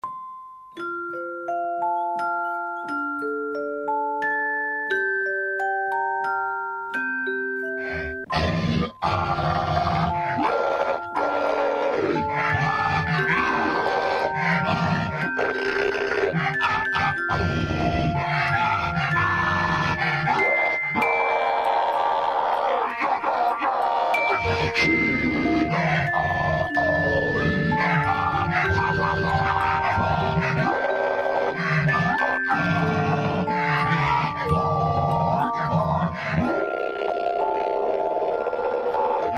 • Качество: 128, Stereo
пугающие
страшные
колыбельная
Колыбельная с голосом монстра